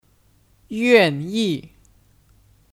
愿意 Yuànyì ( Kata kerja ) : Bersedia